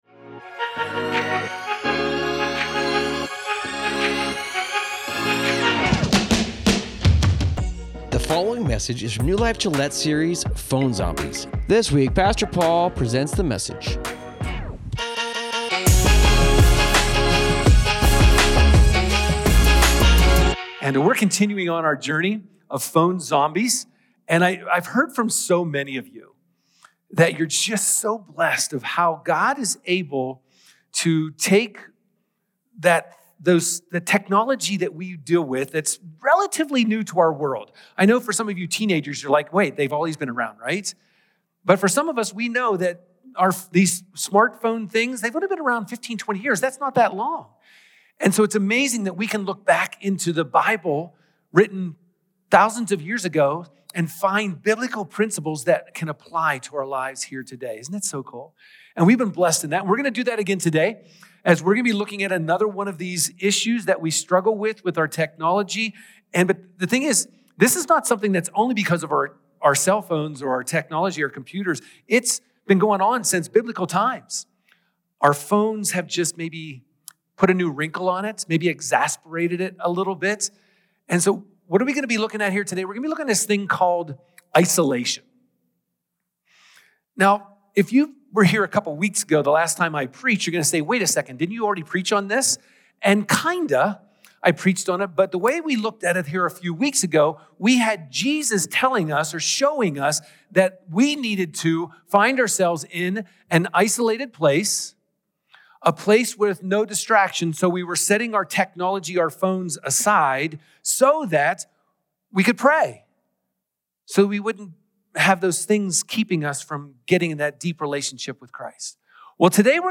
New Life Gillette Church Teachings